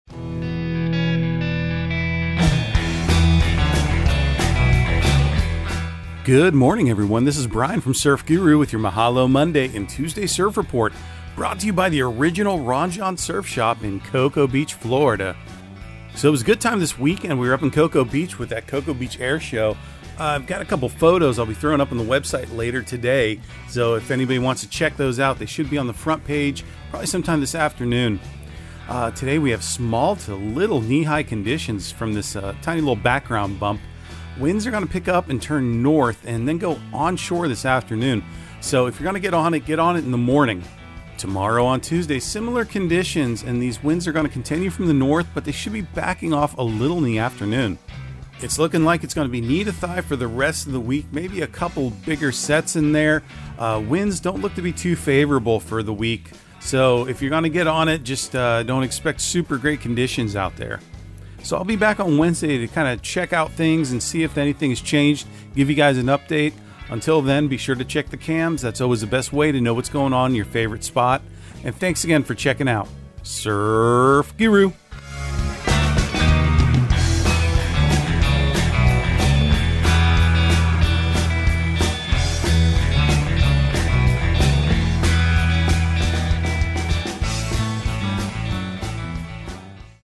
Surf Guru Surf Report and Forecast 04/17/2023 Audio surf report and surf forecast on April 17 for Central Florida and the Southeast.